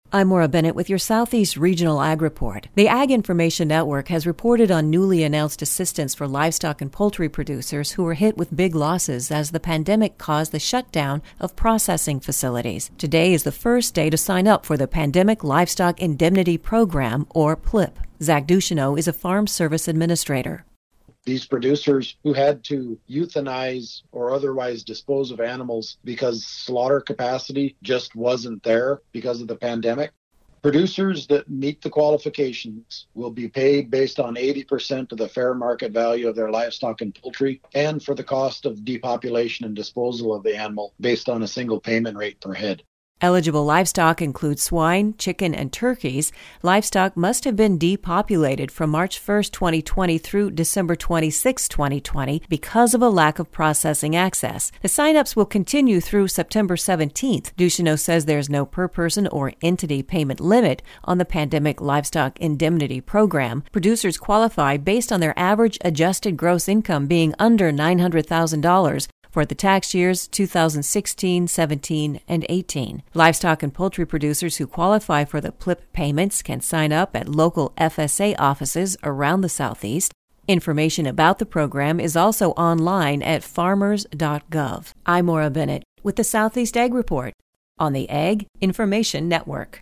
Zach Ducheneaux is a Farm Service Administrator.